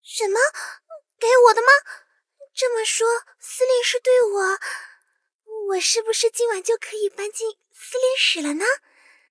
SU-76誓约语音.OGG